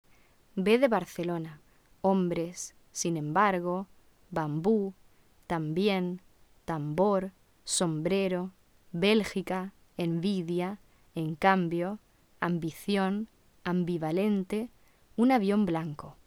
• Una variante oclusiva [b], en cuya pronunciación los labios se cierran momentáneamente e impiden la salida del aire.
[b] de Barcelona (b oclusiva)